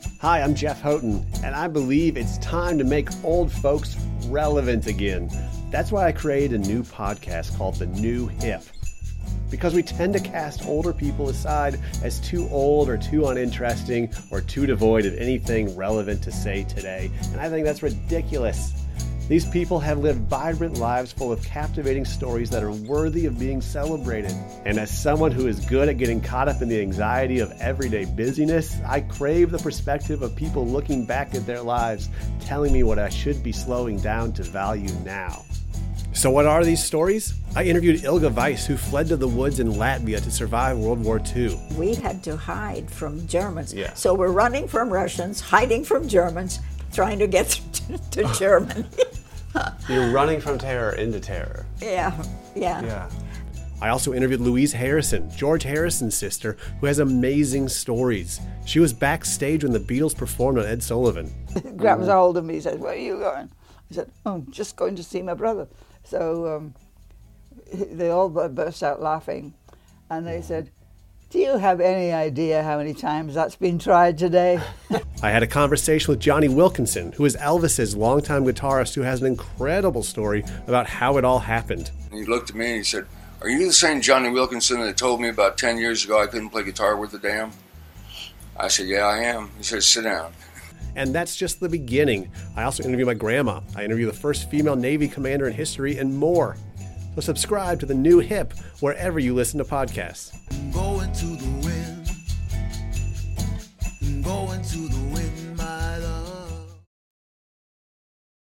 Trailer: